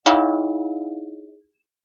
Thin bell ding 3
bell chime ding dong short sound effect free sound royalty free Sound Effects